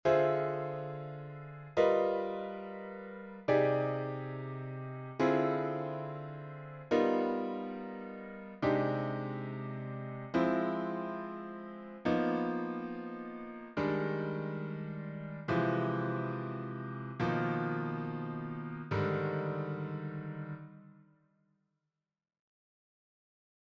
Improvisation Piano Jazz